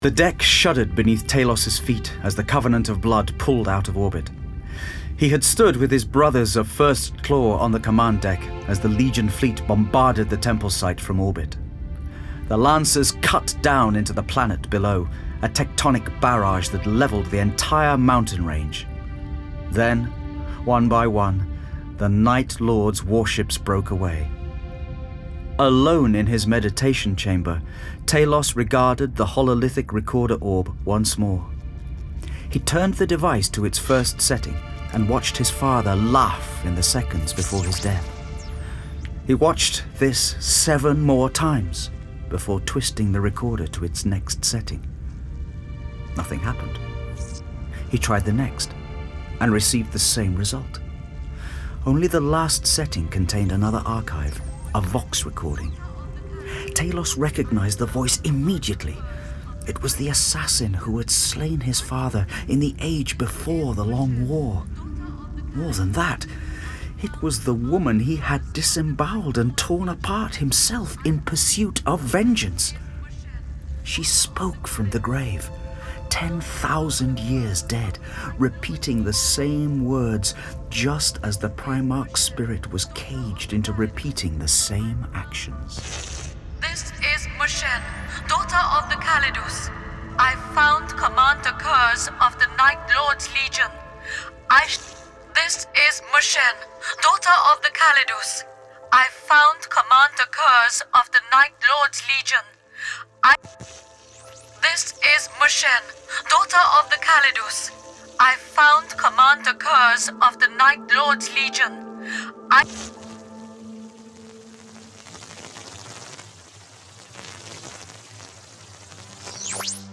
Index of /Games/MothTrove/Black Library/Warhammer 40,000/Audiobooks/Night Lords Trilogy/Throne of Lies